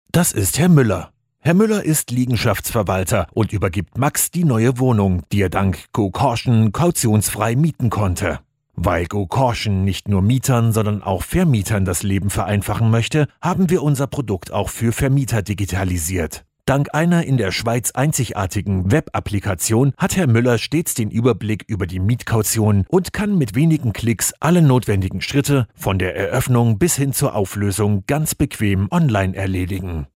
A modern, warm and dynamic voice equally at home in his native German or english
Commercial, Bright, Upbeat, Conversational